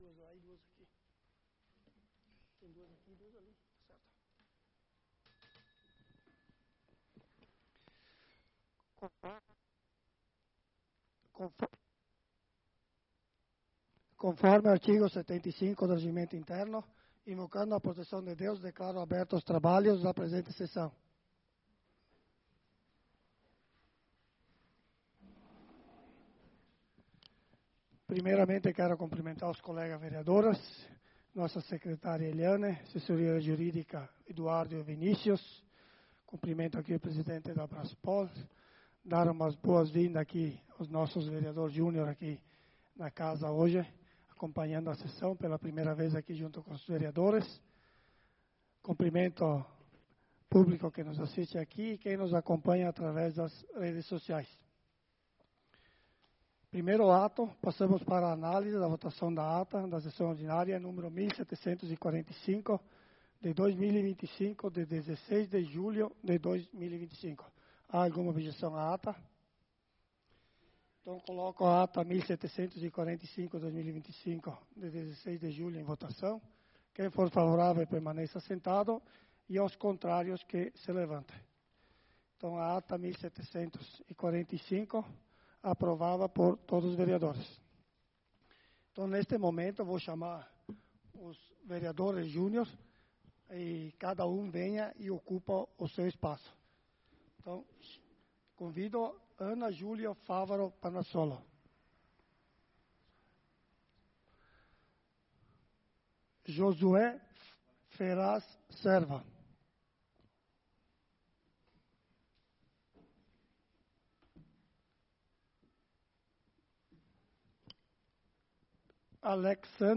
Sessão Ordinária do dia 06/08/2025